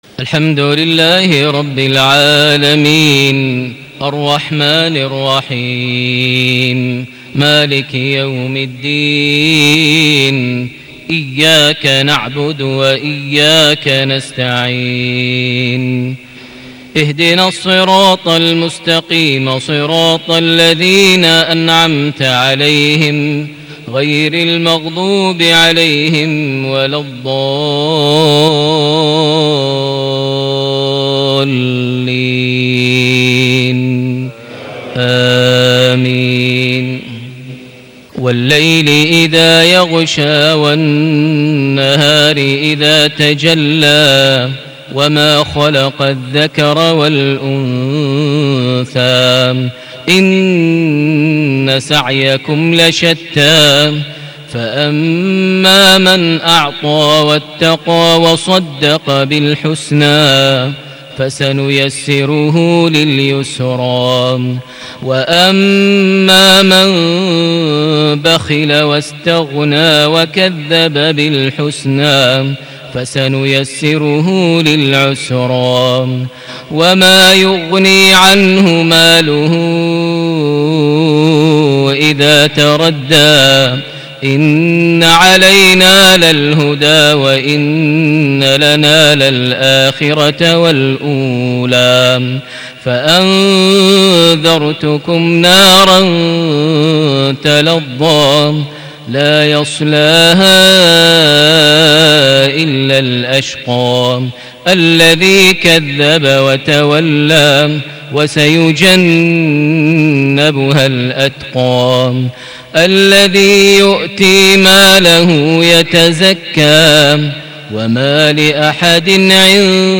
صلاة المغرب ٦ جماد الآخر ١٤٣٨هـ سورتي الليل / القارعة > 1438 هـ > الفروض - تلاوات ماهر المعيقلي